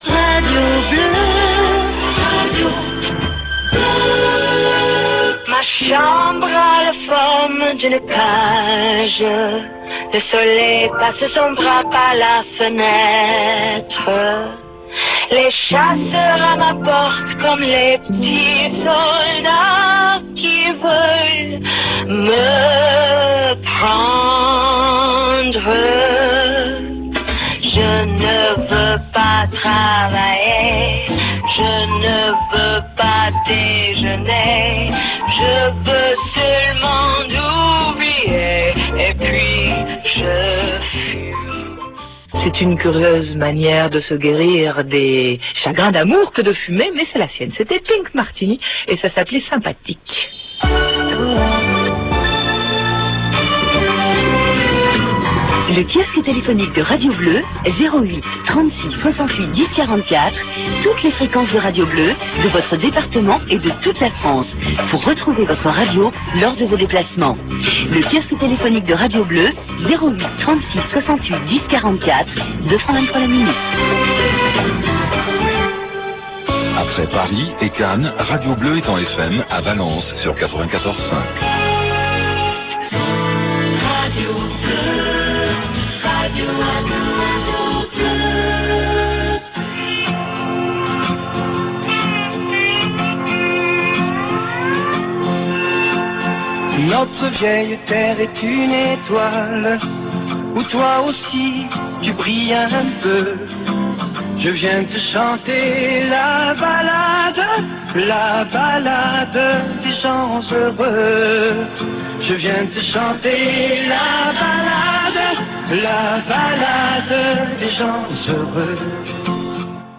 OM-MW 864 kHz - 300 kW
Extraits de R. BLEUE/FRANCE BLEU en AM STEREO,